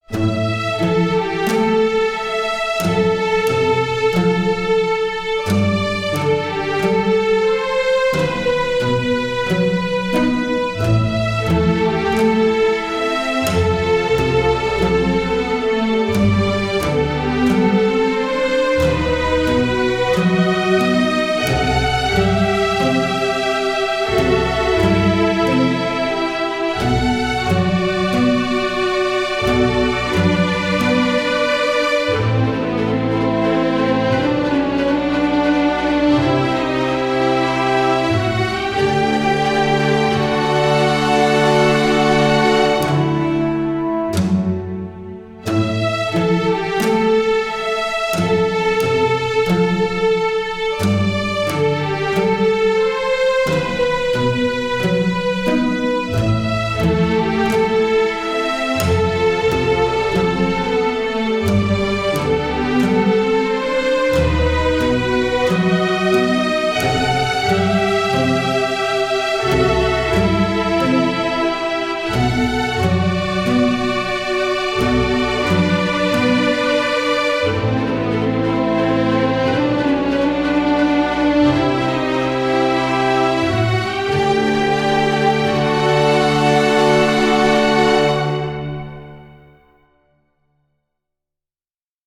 YouTubeやゲーム等に使えるフリーBGM